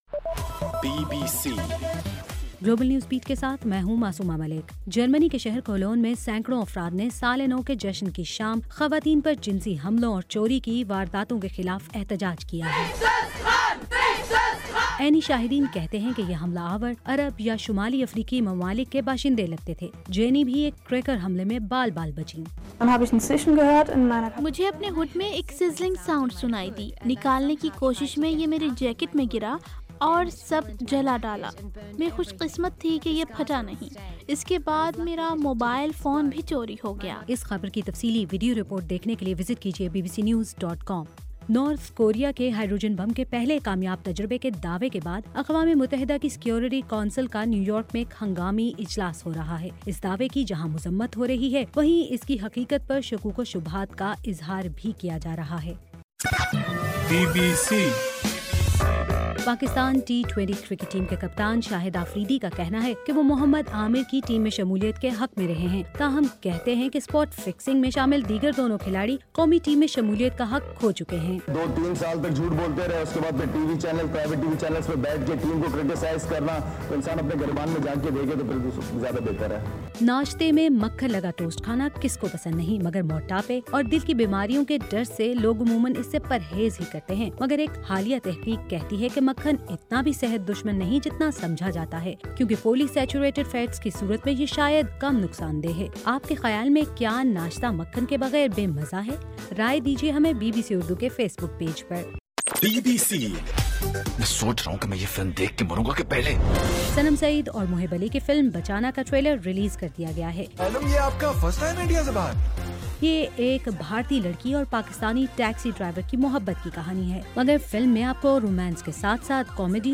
جنوری 06: رات 11 بجے کا گلوبل نیوز بیٹ بُلیٹن